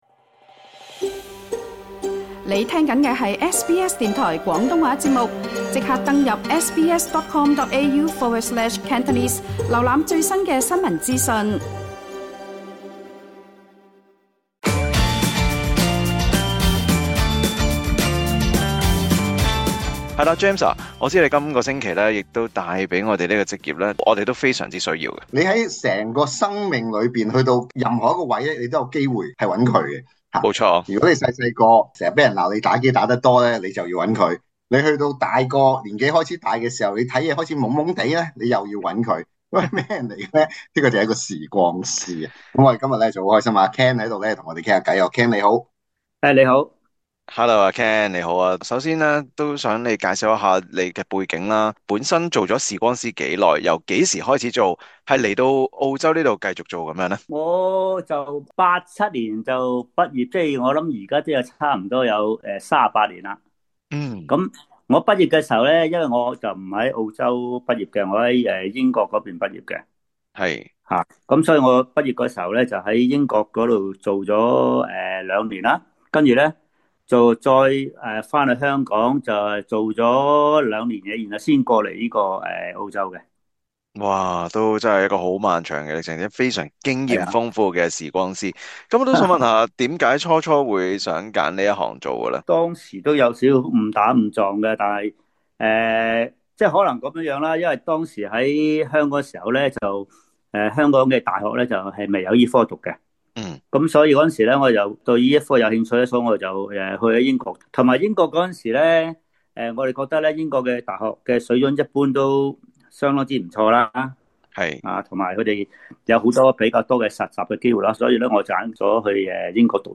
今集【創業搵食GUIDE】，請來一位在香港當了多年視光師，分享他移澳後加盟了一間大型連鎖眼鏡店繼續本科行業的點滴。